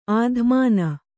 ♪ ādhmāna